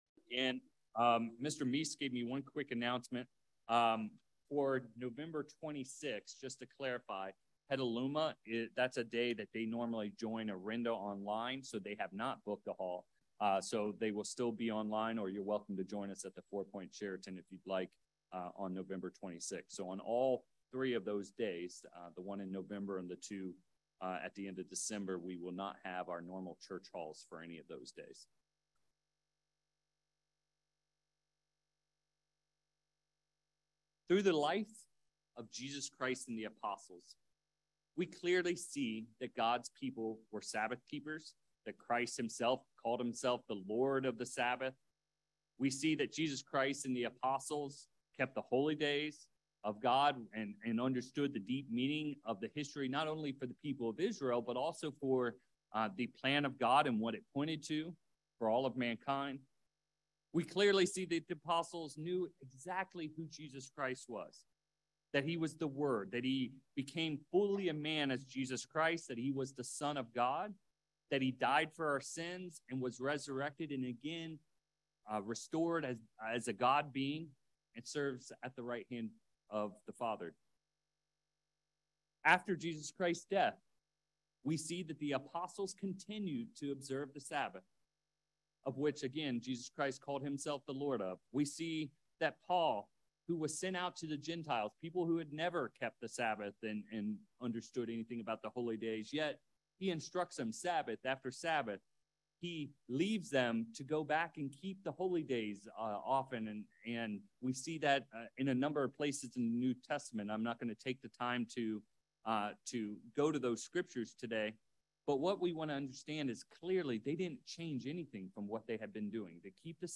Over the next several hundred years, Christian beliefs radically changed. This sermon dives into that period of time to examine what happened to those of the Way and how they went astray.